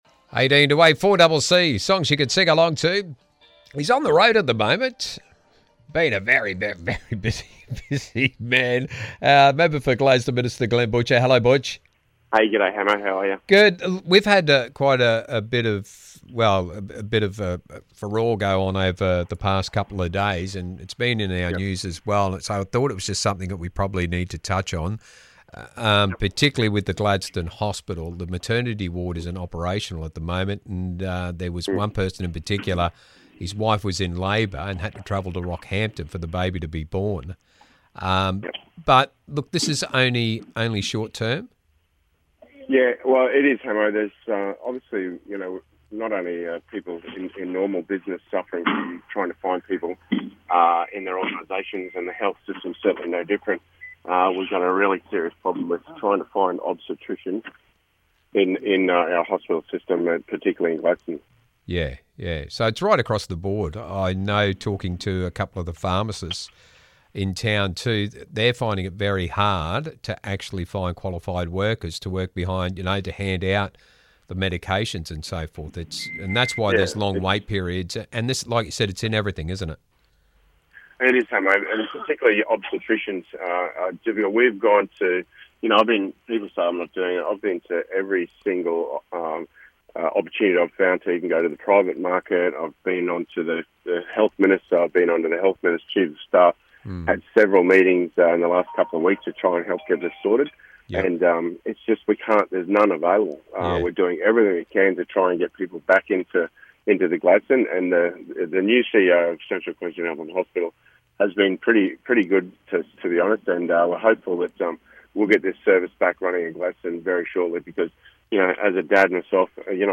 Member for Gladstone Minister Glenn Butcher speaks about the Maternity Ward at Gladstone Hospital